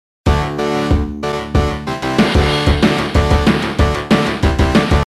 Videogame music and sound effects